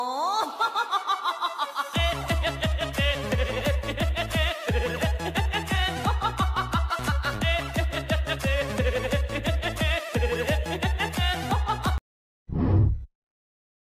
Nhạc Chọc quê
Thể loại: Âm thanh meme Việt Nam
Description: Nhạc chọc quê là dạng sound effect hài hước thường dùng khi chỉnh sửa video giải trí, meme hoặc clip troll trên mạng xã hội, nhằm làm nổi bật khoảnh khắc bị trêu chọc hay tình huống ngượng ngùng.